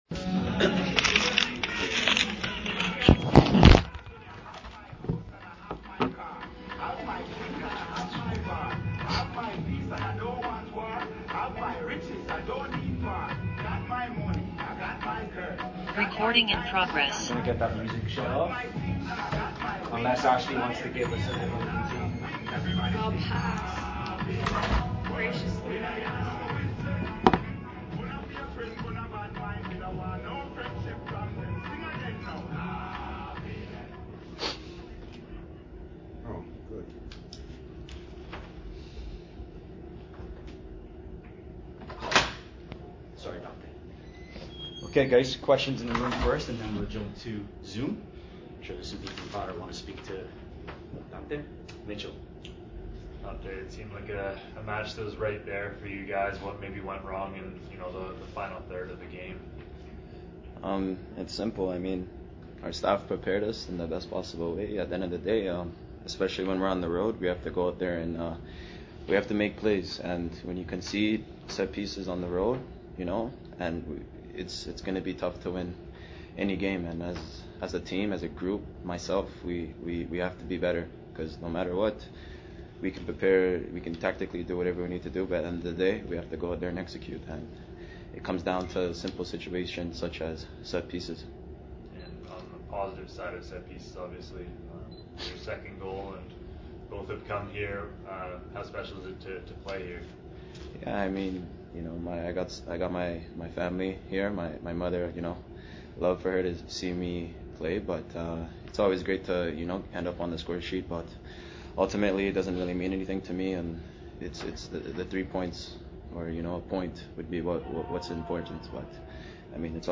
2024...post game press conference after the York United FC vs Valour FC CPL game